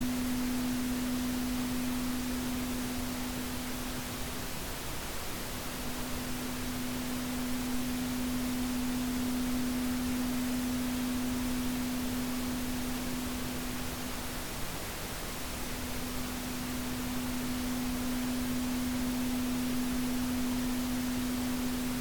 Глубокие низкие частоты создают успокаивающий эффект, помогая отвлечься от стресса.
Коричневый шум и его звучание